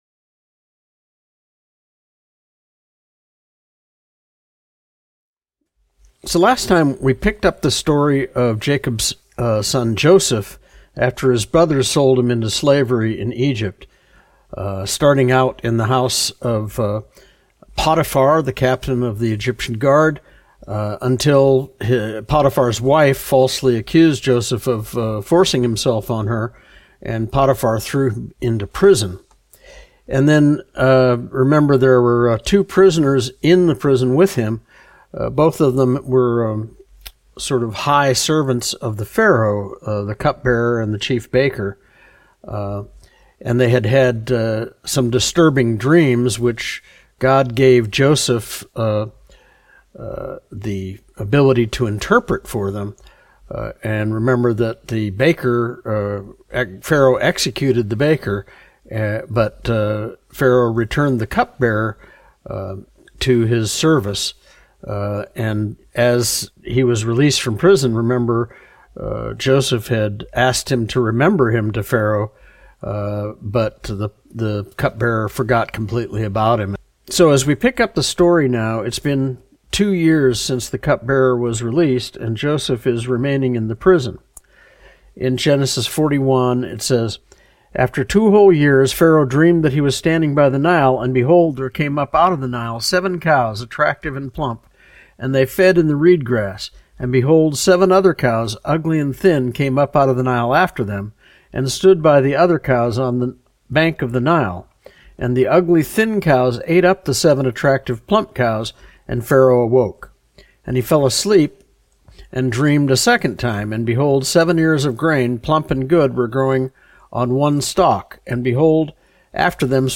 Study Type - Adult Lesson